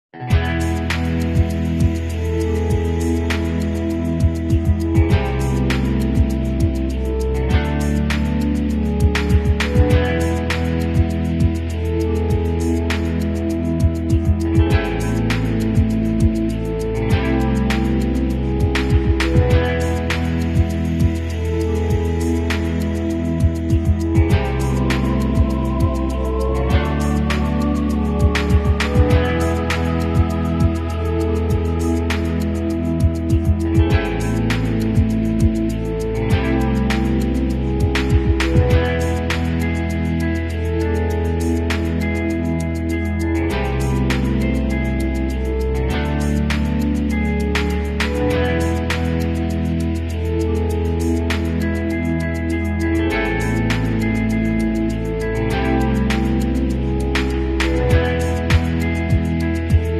ASMR V Bucks Slicing | A sound effects free download
ASMR V-Bucks Slicing | A Clean Cut Reveals Glowing Layers Inside Relaxing & Satisfying